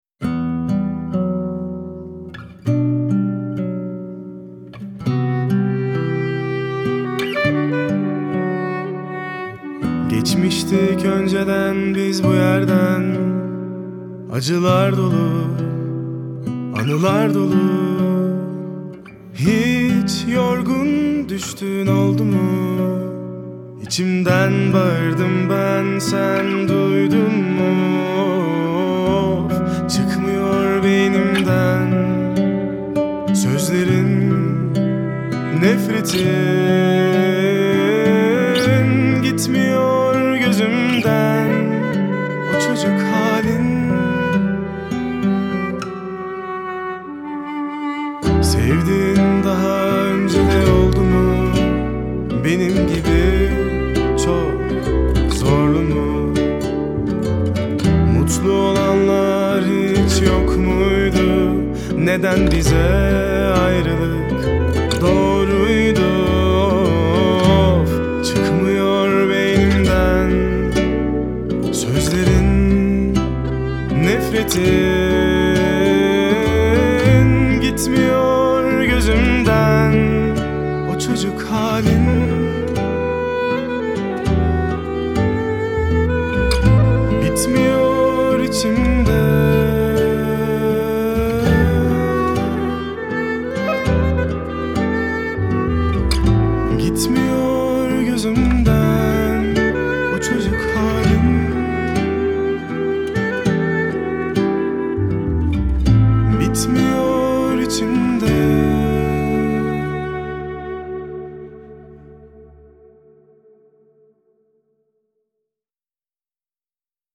duygusal hüzünlü üzgün şarkı.